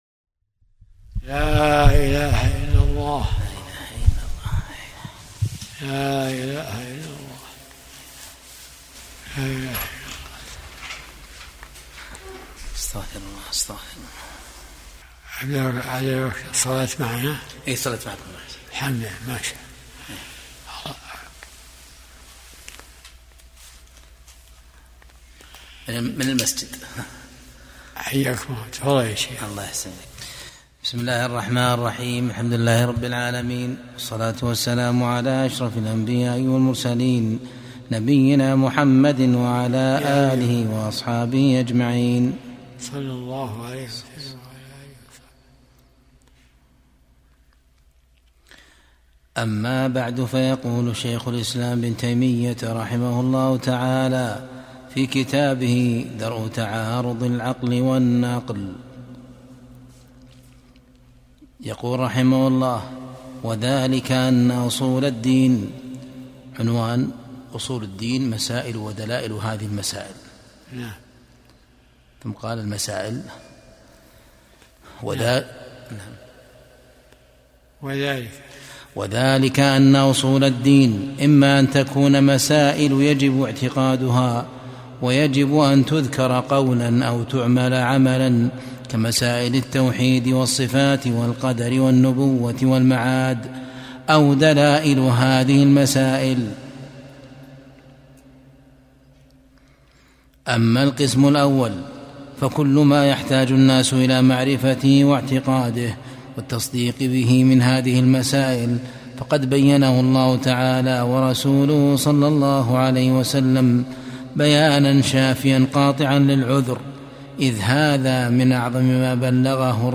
درس الأربعاء 62